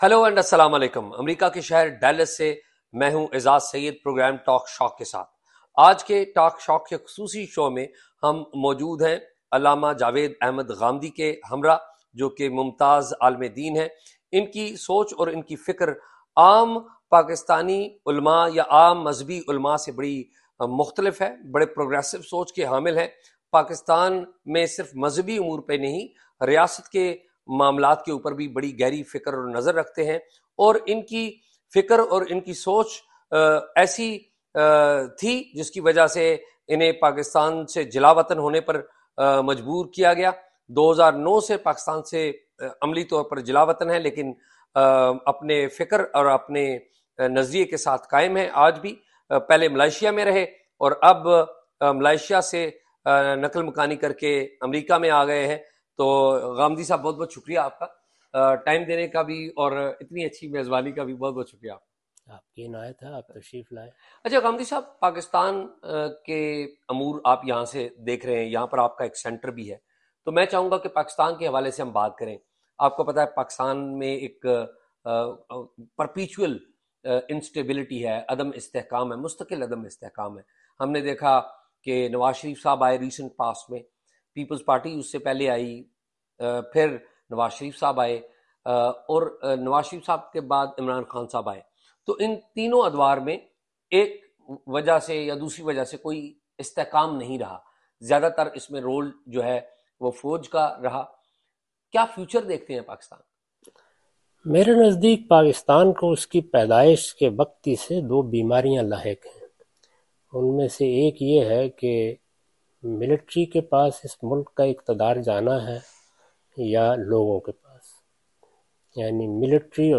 Exclusive interview from USA.